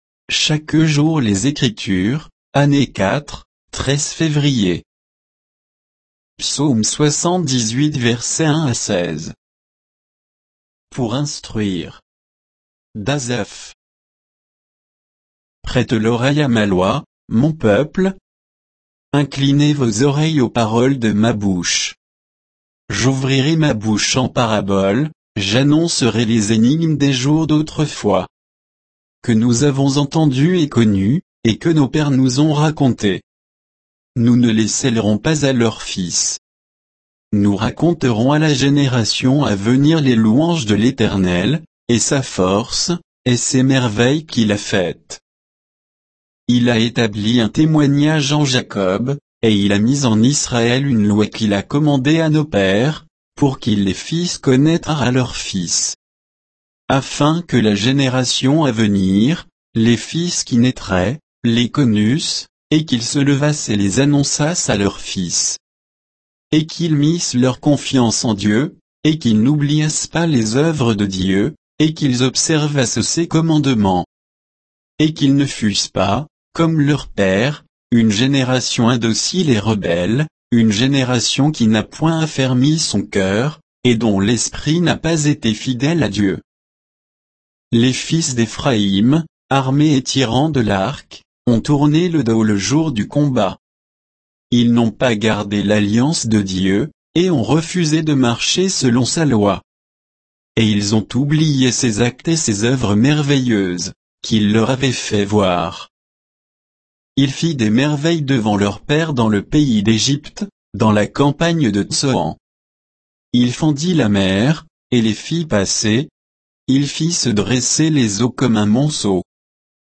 Méditation quoditienne de Chaque jour les Écritures sur Psaume 78